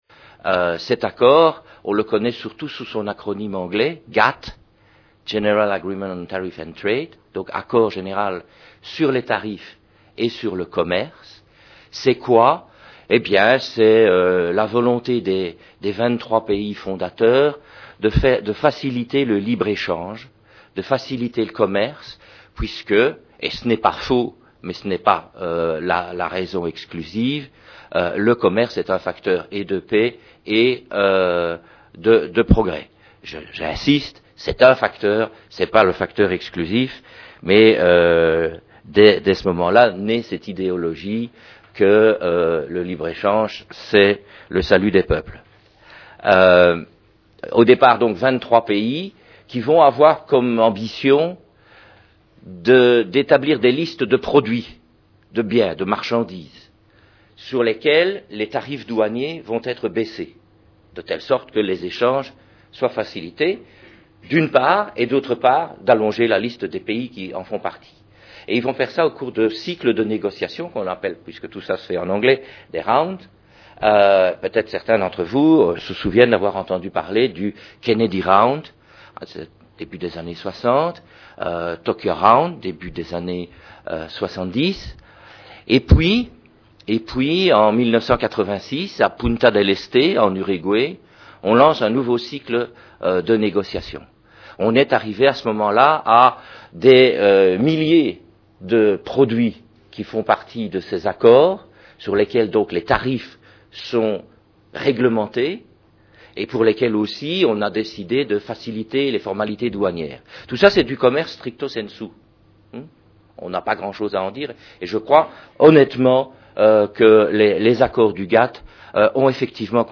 Conférence au Théâtre de la Colline, Paris, septembre 2003
I - La conférence